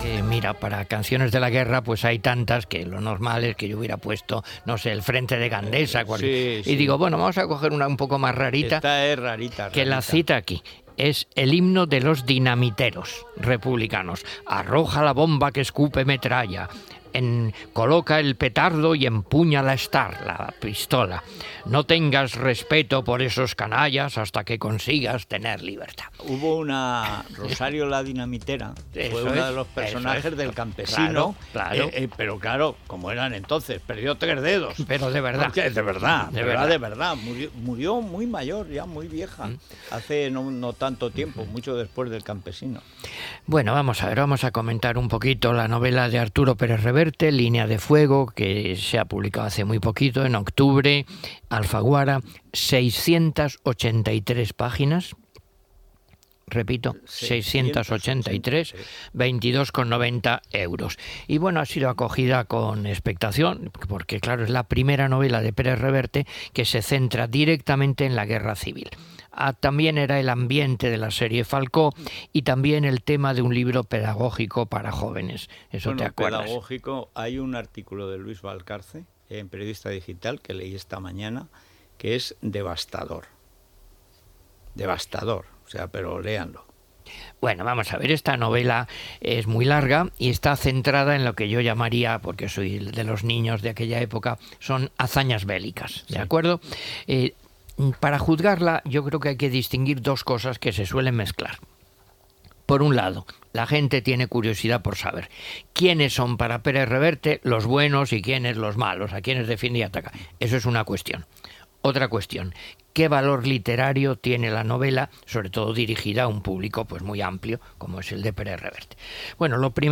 Comentari del llibre "La línea de fuego" escrita per Arturo Pérez-Reverte Gènere radiofònic Info-entreteniment